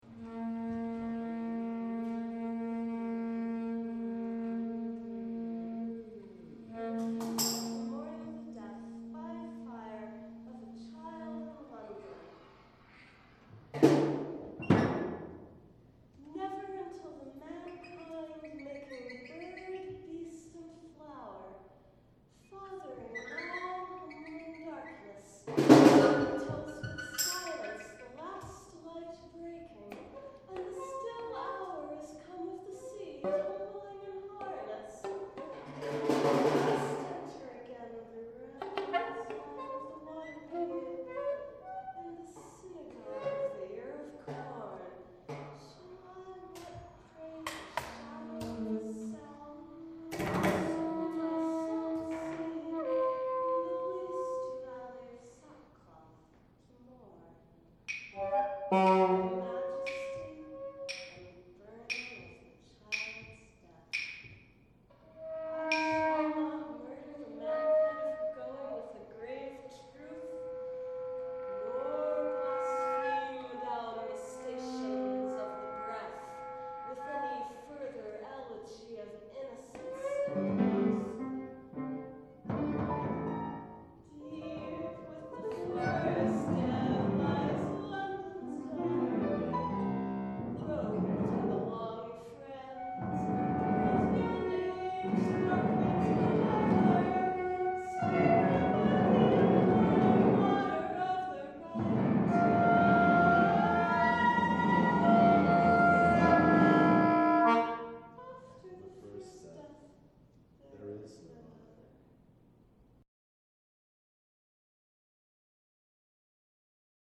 The text is quiet on a lot of these, which I think may be due to the acoustics of the Sherwood Room.
“Refusal,” soprano sax, percussion & text as musical element:
Recorded June 12, 2010, in the Sherwood Room, Levering Hall, JHU Homewood campus
vox, soprano sax
vox, piano, percussion